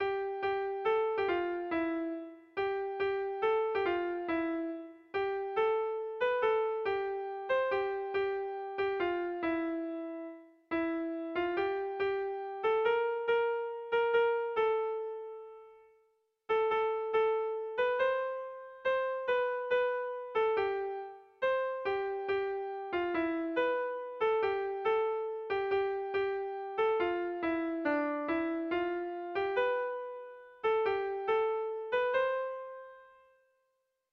Air de bertsos - Voir fiche   Pour savoir plus sur cette section
Zazpi puntuko berezia
ABDEF